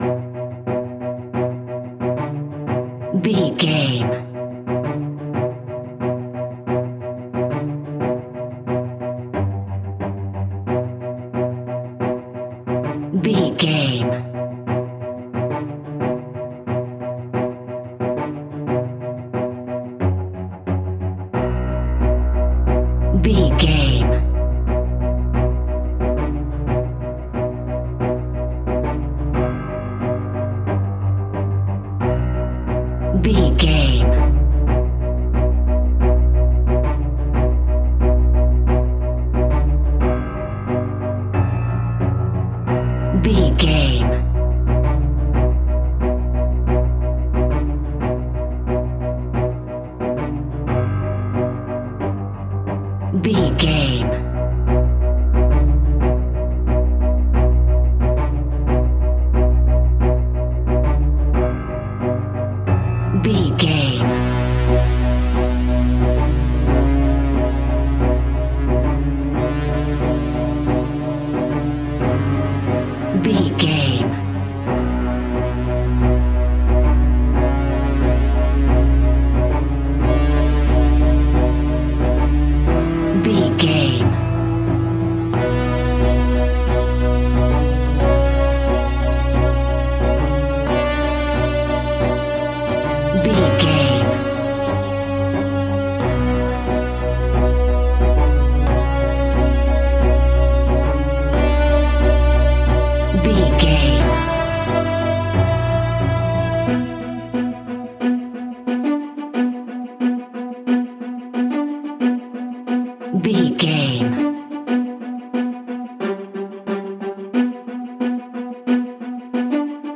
In-crescendo
Aeolian/Minor
scary
ominous
dark
eerie
strings
piano
ticking
electronic music